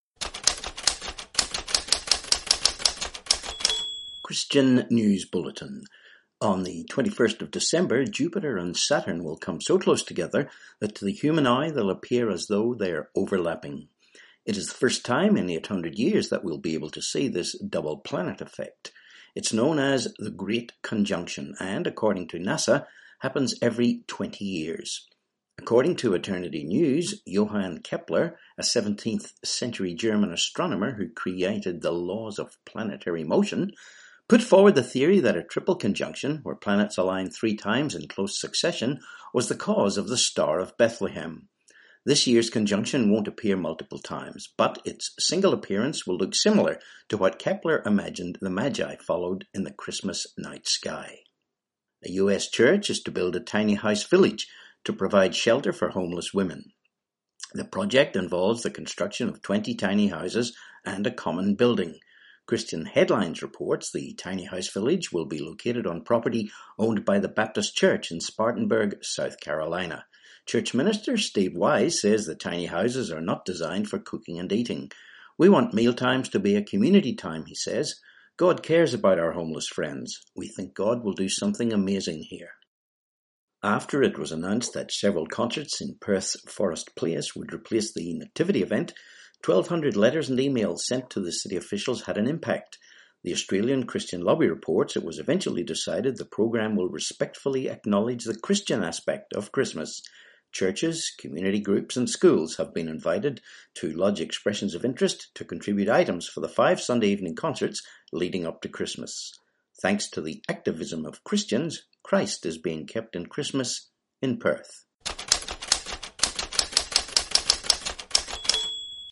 20dec20 Christian News Bulletin